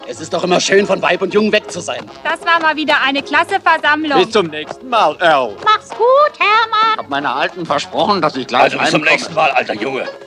- div. Logenmitglieder